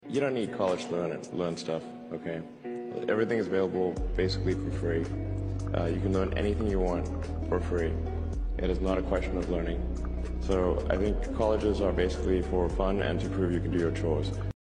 Elon musk motivation speech richest sound effects free download